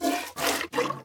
minecraft / sounds / entity / cow / milk2.ogg
milk2.ogg